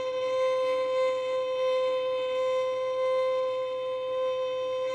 以不同的表达方式演奏长的持续音符；音高、动态和表达方式（正常、谐音、sul tasto、sul pont）都在文件名中。 用一对Neumann话筒进行录音
标签： 笔记 高亢 刺耳的怪声 维持 小提琴
声道立体声